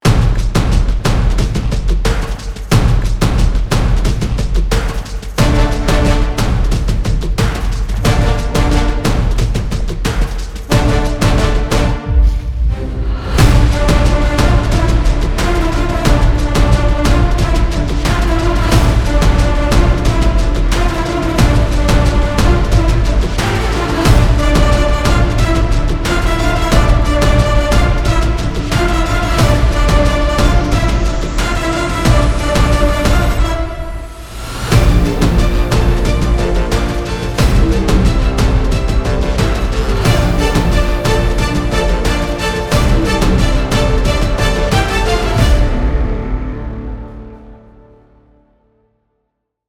without dialogues and unwanted sounds